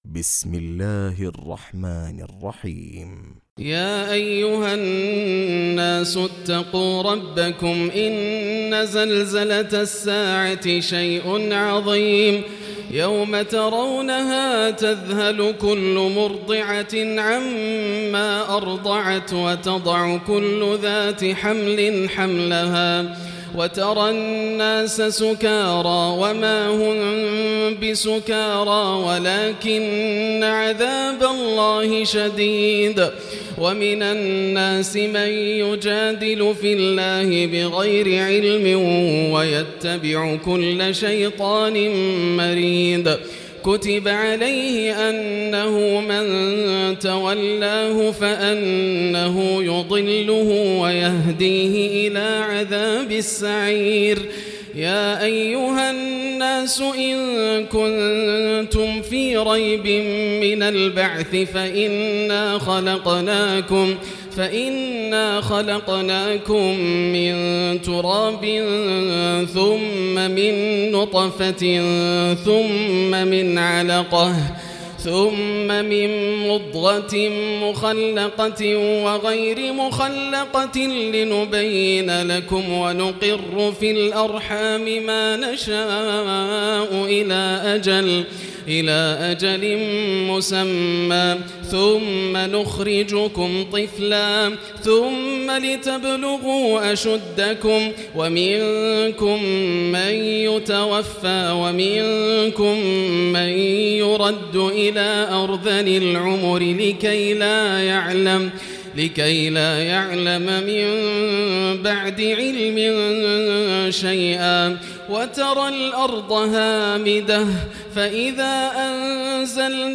تراويح الليلة السادسة عشر رمضان 1439هـ سورة الحج كاملة Taraweeh 16 st night Ramadan 1439H from Surah Al-Hajj > تراويح الحرم المكي عام 1439 🕋 > التراويح - تلاوات الحرمين